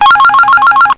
Phone.mp3